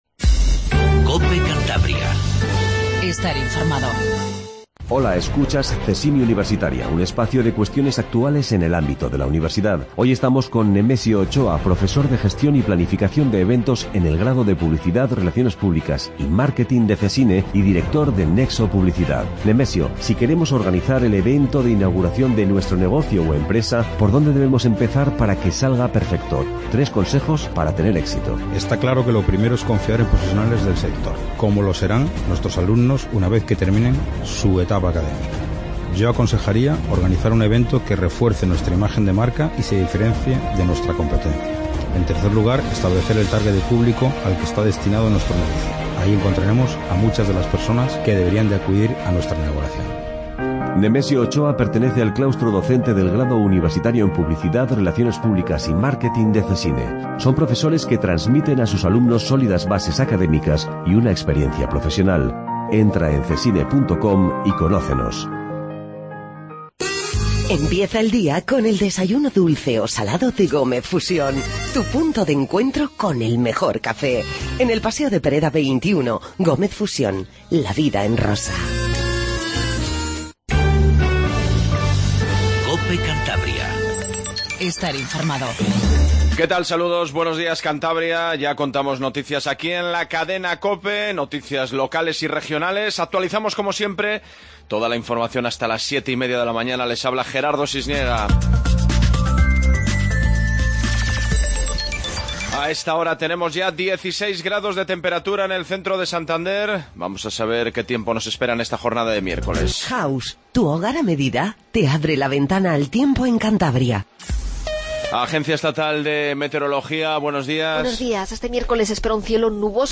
INFORMATIVO MATINAL 07:20